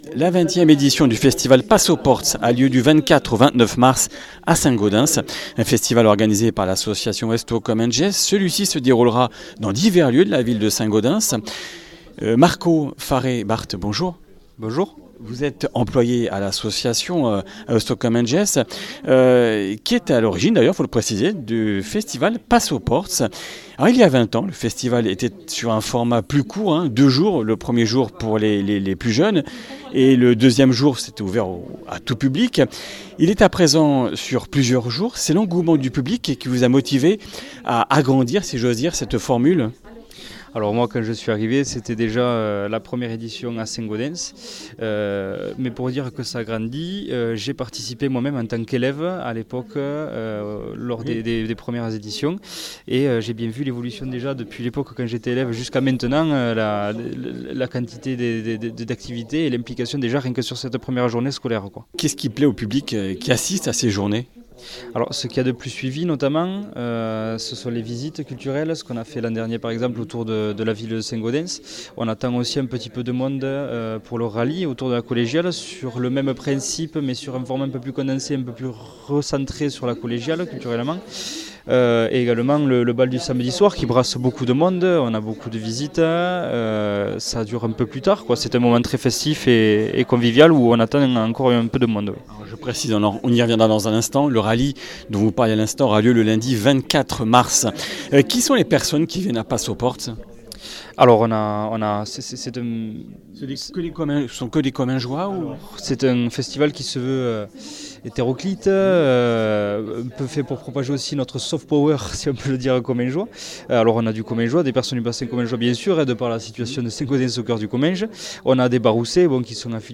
Comminges Interviews du 17 mars